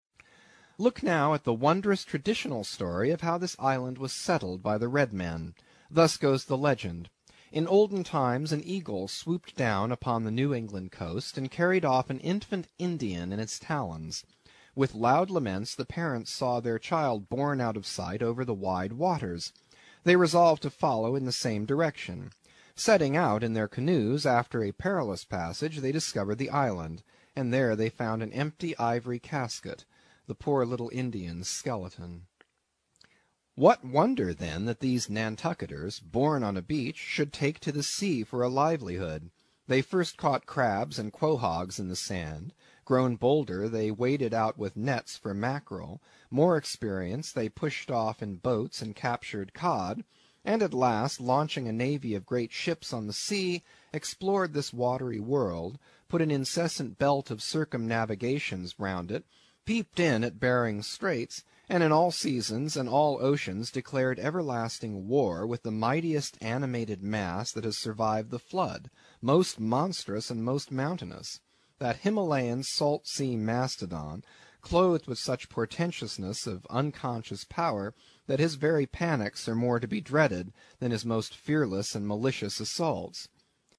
英语听书《白鲸记》第51期 听力文件下载—在线英语听力室